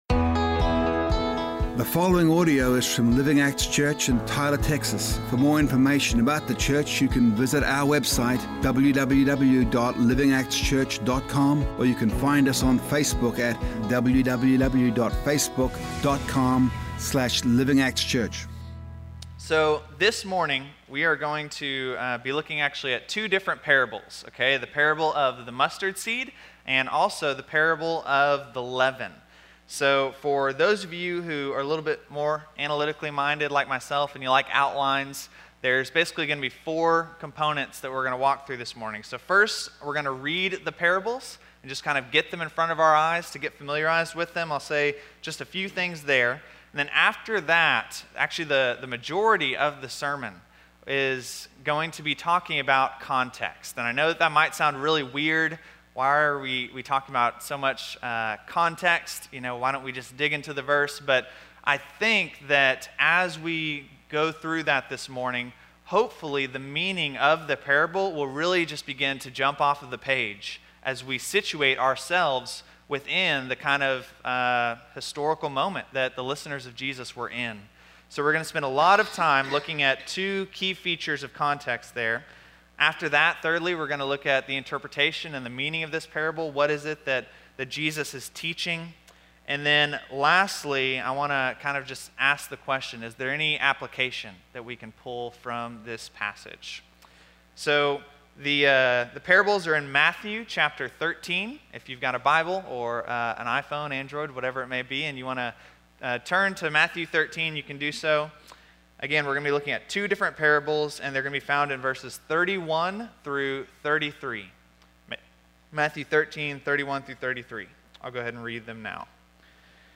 A message from the series "1 John."